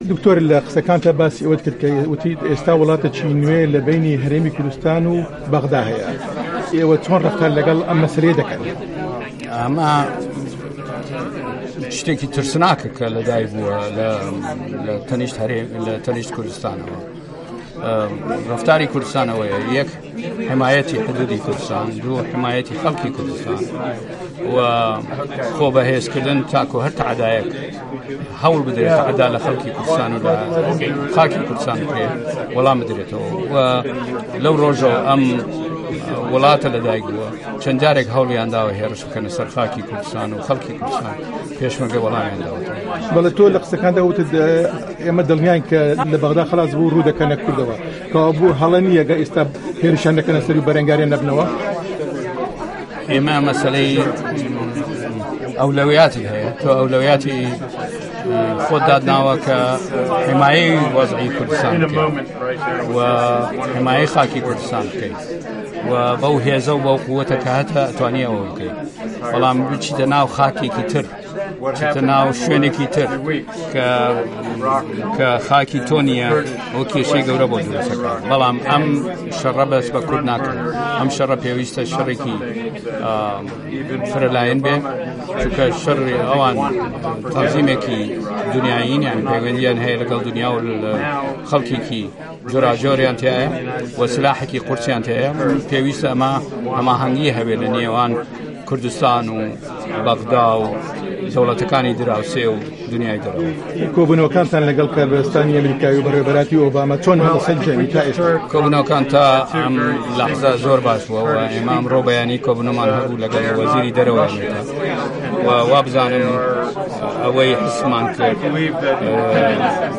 وتووێژی دکتۆر فوئاد حسێن